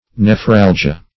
Nephralgia \Ne*phral"gi*a\, Nephralgy \Ne*phral"gy\, n. [NL.